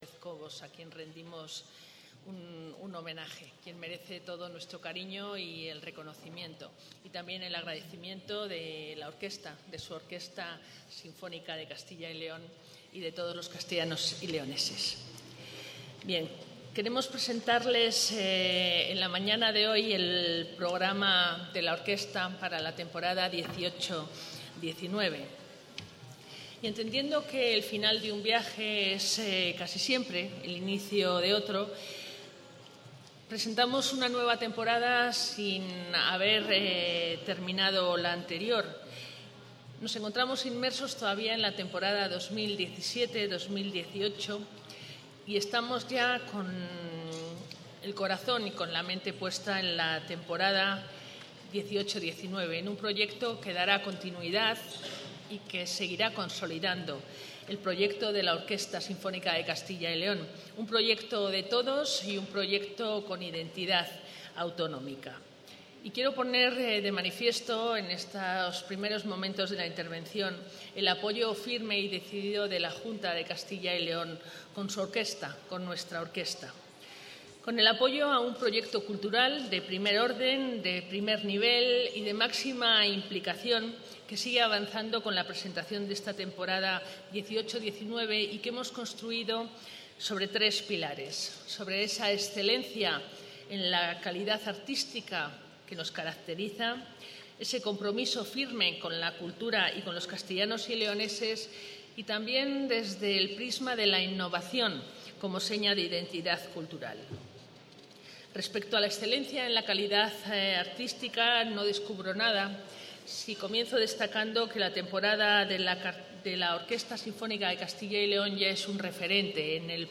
La consejera de Cultura y Turismo, María Josefa García Cirac, ha presentado hoy la nueva temporada de la Orquesta Sinfónica de...